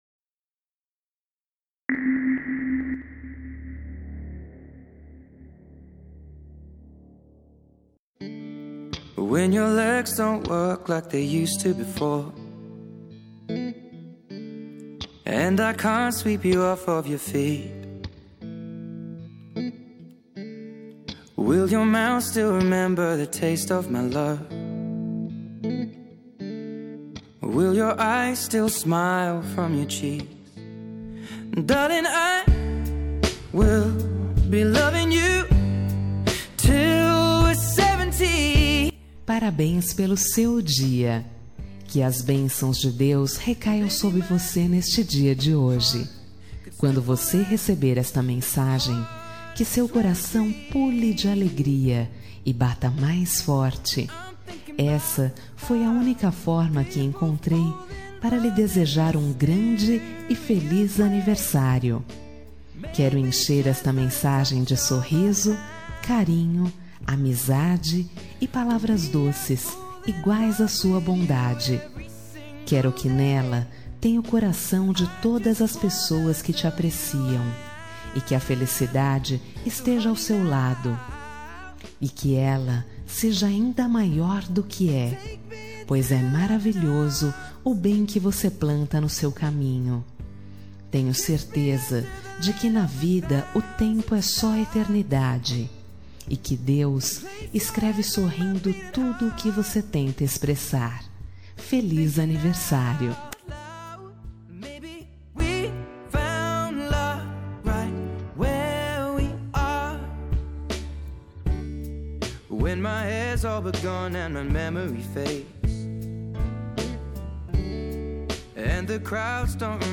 Aniversário de Ficante – Voz Feminina – Cód: 88799